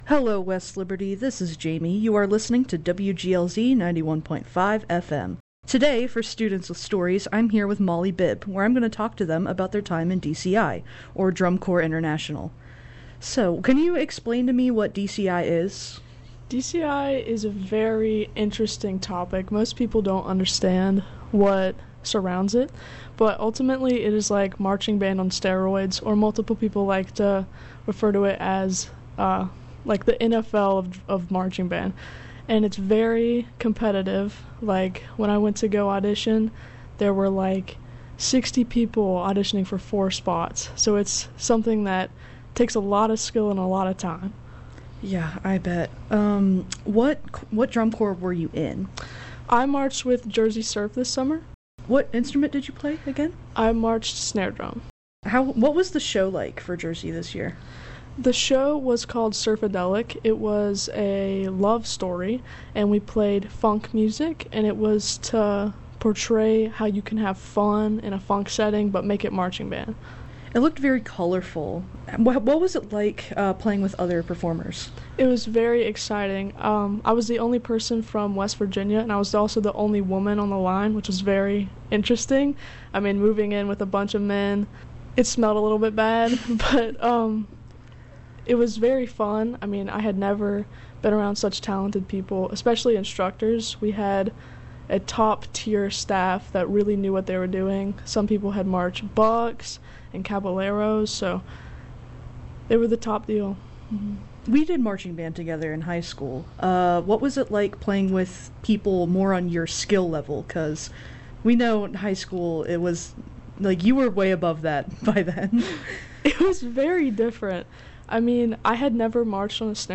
In this Students with Stories interview